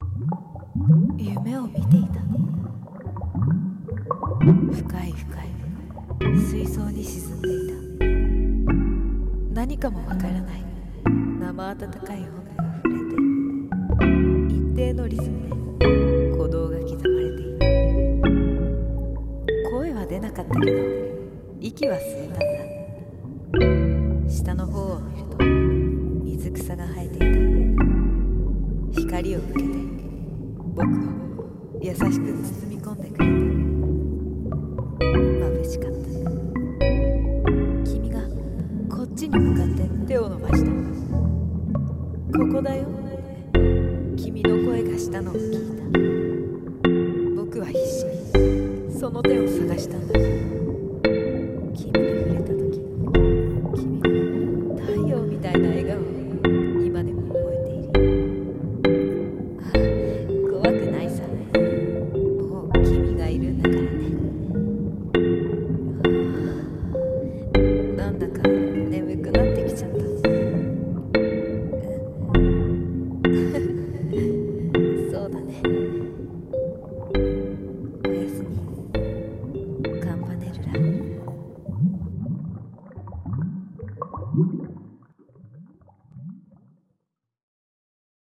【1人声劇】おやすみ、カンパネルラ